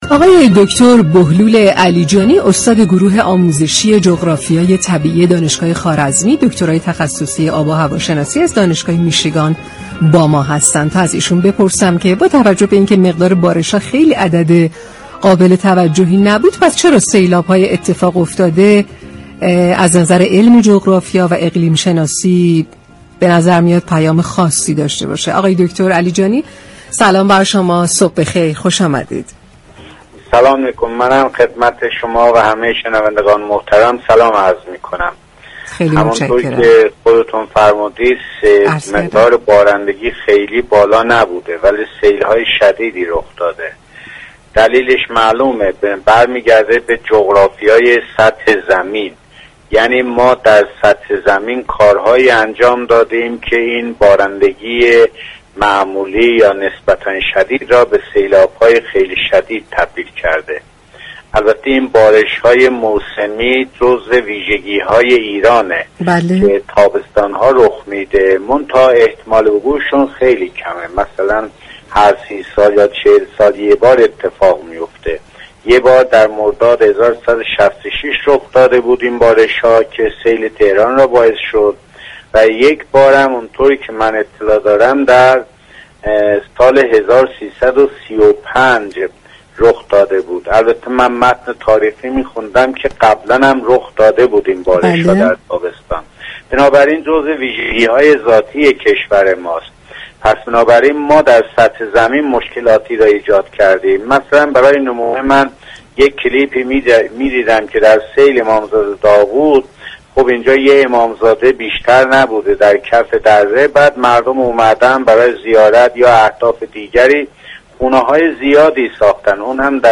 در گفت‌و‌گو با شهرآفتاب رادیو تهران